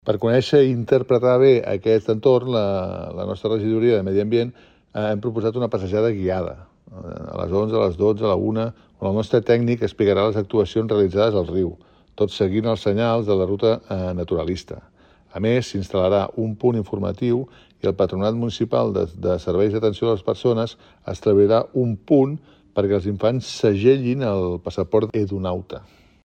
Jordi Amat, regidor de Medi Ambient i Benestar Animal de l'Ajuntament